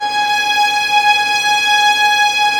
Index of /90_sSampleCDs/Roland - String Master Series/STR_Vlns Bow FX/STR_Vls Pont wh%